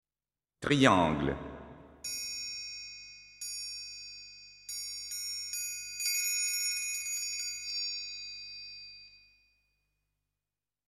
Triángulo.mp3